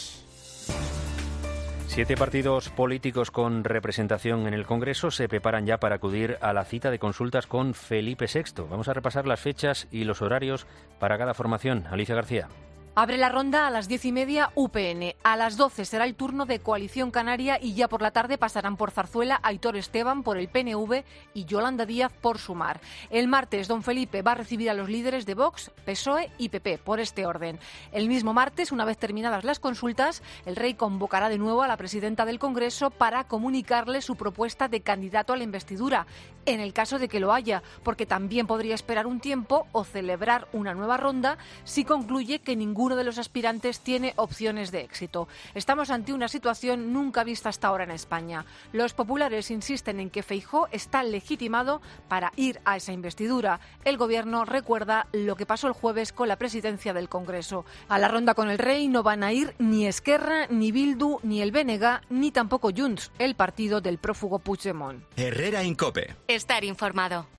crónica